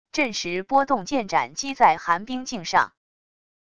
震石波动剑斩击在寒冰镜上wav音频